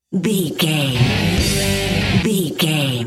Aeolian/Minor
drums
electric guitar
bass guitar
Sports Rock
hard rock
aggressive
energetic
intense
powerful
nu metal
alternative metal